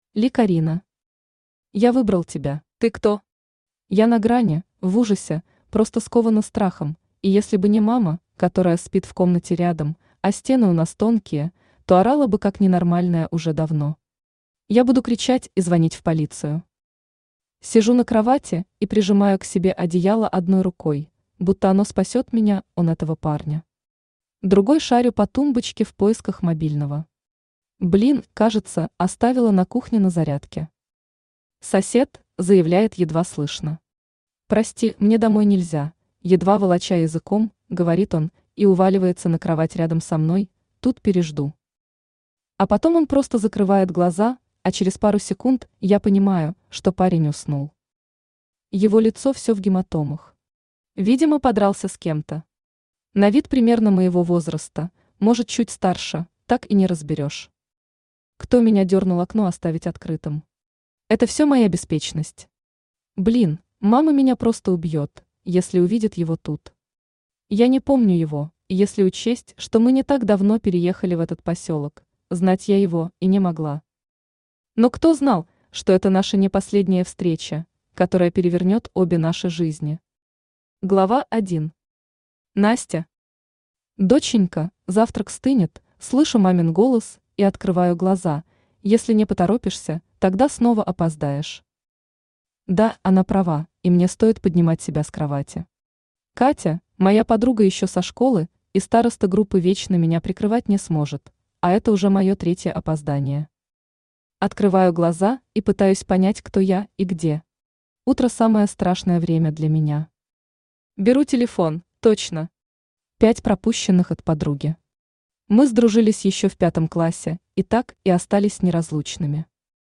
Аудиокнига Я выбрал тебя | Библиотека аудиокниг
Aудиокнига Я выбрал тебя Автор Ли Карина Читает аудиокнигу Авточтец ЛитРес.